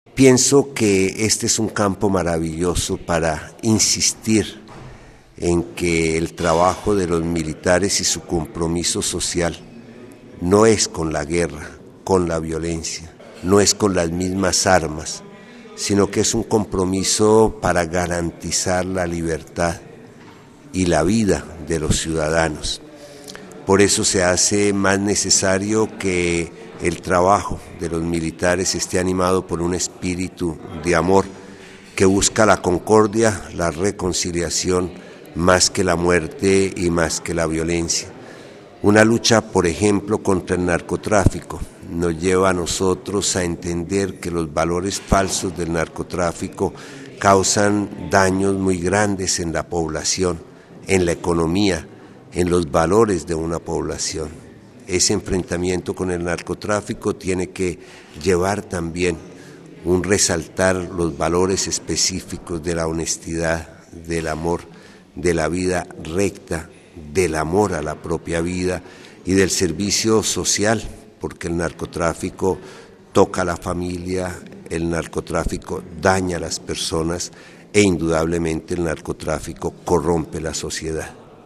Entrevista al obispo castrense de Colombia, monseñor Suescun